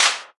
打击乐 " 拍手1
描述：合成的手拍声。
Tag: 合成器 打击乐